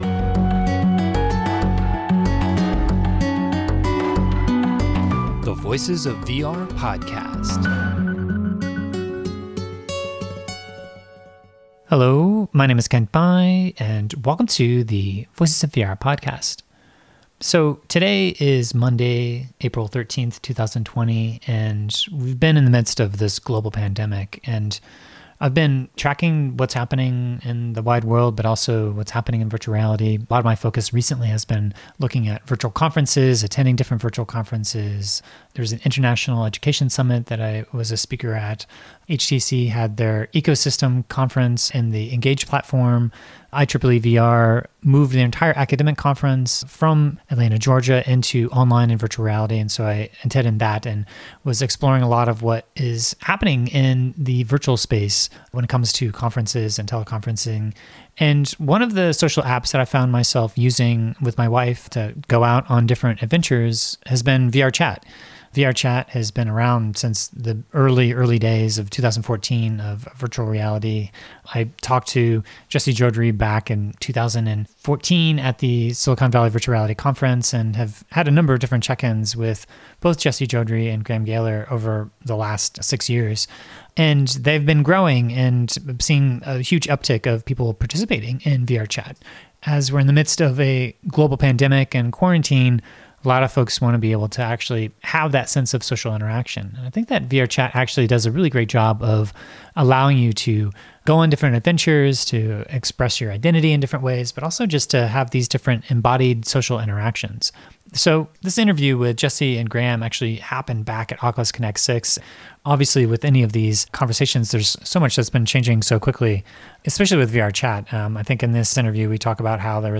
At the time of this interview, they had hundreds of thousands of worlds and over 5 million avatars. They talk about how they manage moderation through community labs moderation as well as a trust system that helps them identify potentially problematic creators.